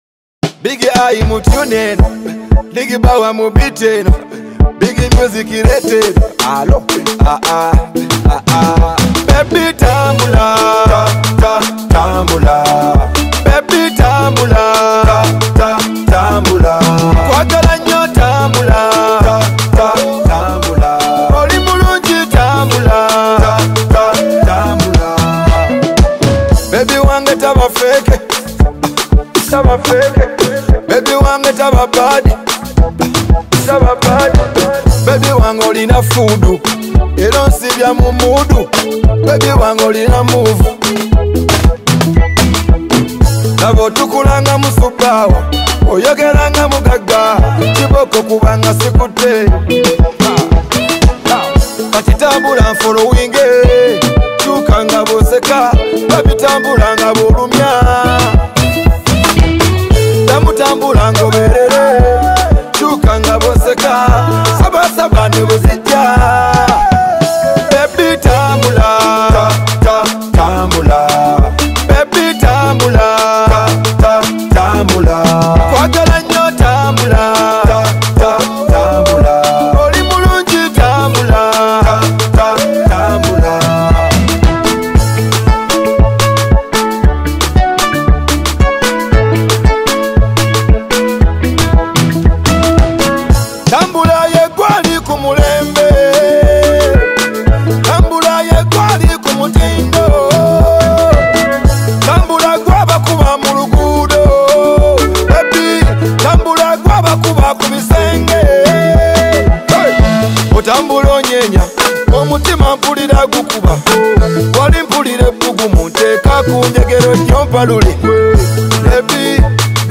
Genre: Dance Hall